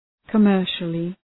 {kə’mɜ:rʃəlı}